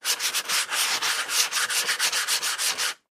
in_sandpaper_02_hpx
Wood being sanded by hand. Tools, Hand Wood, Sanding Carpentry, Build